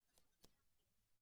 Part_Assembly_23.wav